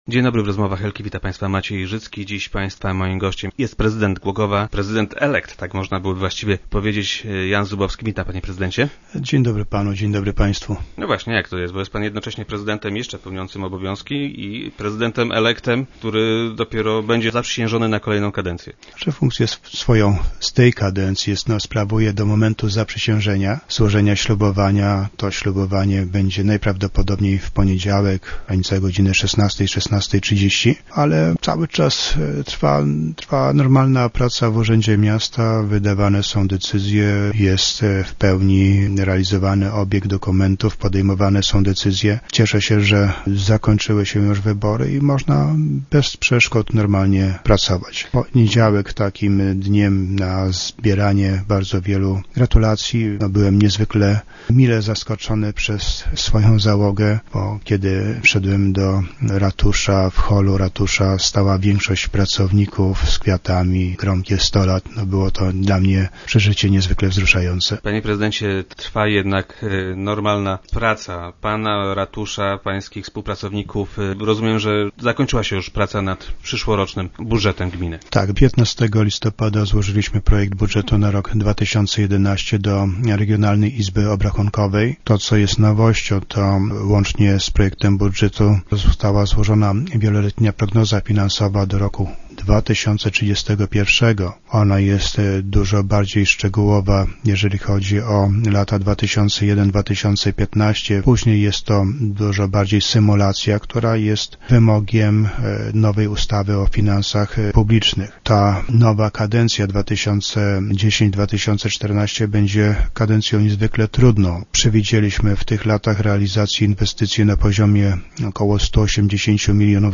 - W tej chwili projektem zajmuje się Regionalna Izba Obrachunkowa - powiedział nam prezydent Jan Zubowski, który był dziś gościem Rozmów Elki.